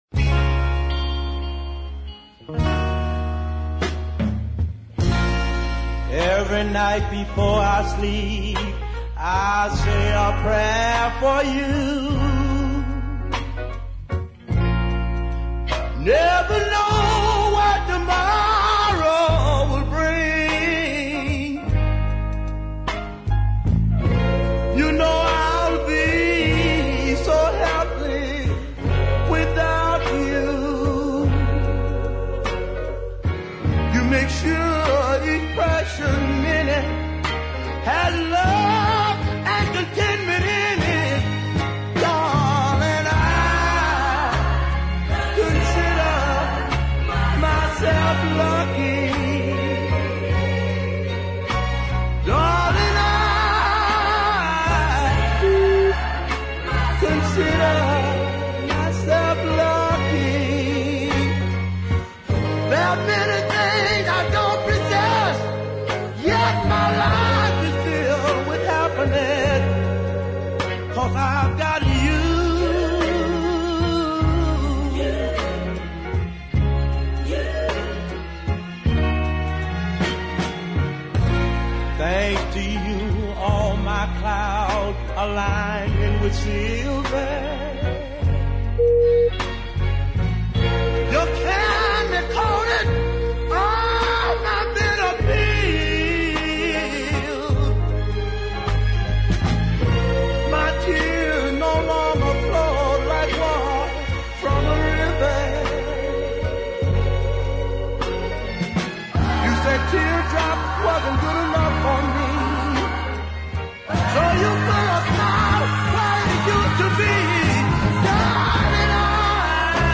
bluesy deep